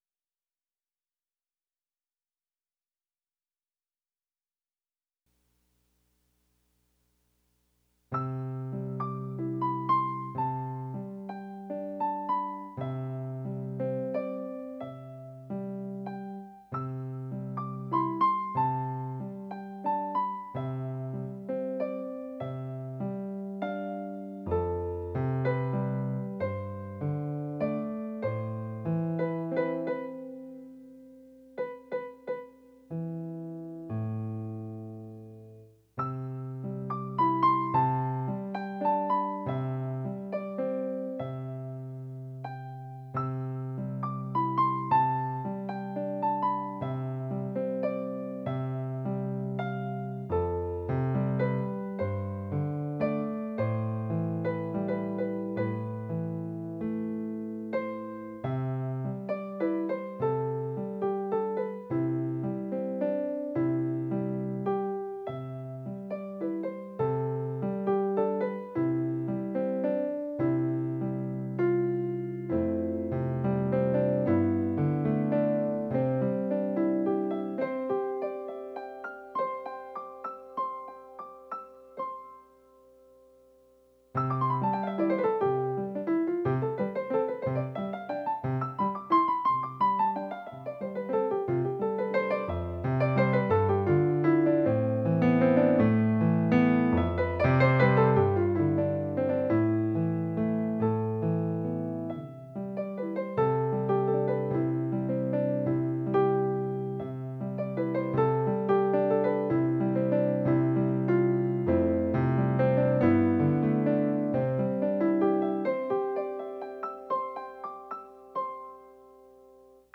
Projet de musique presque  »Live » .
Elles sont des instantannés  de ce qui se trament, de ce qui surgit de nulle part.
09.07.03 A PRTK PIANO 1ST 5 a 7